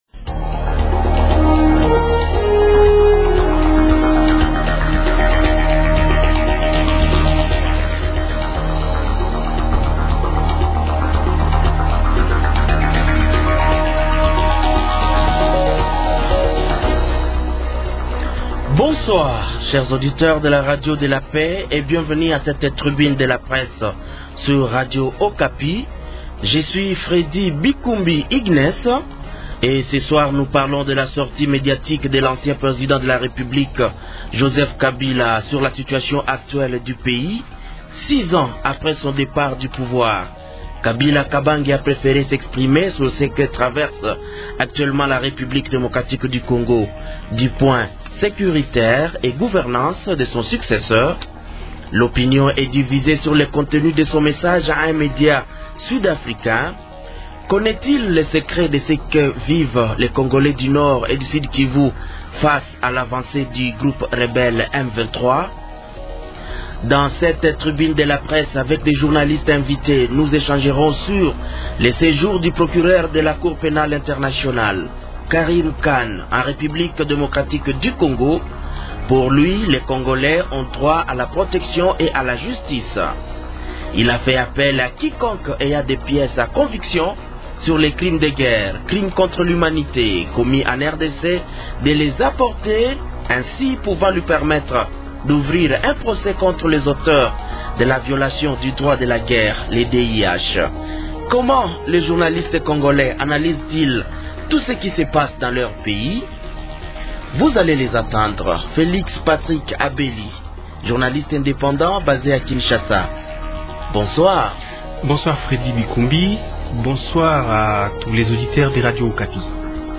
Dans cette tribune de la presse avec des journalistes invités, nous échangerons aussi sur le séjour du procureur de la Cour pénale internationale, Karim Khan, en RDC.